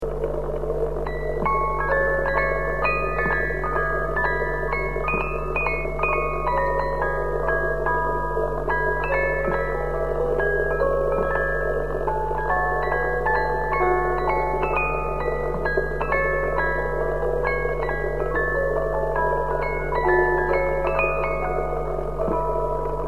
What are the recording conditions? Needless to say that the quality of these recordings is poor, if measured with nowadays (CD) standards.